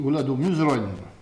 Patois
Catégorie Locution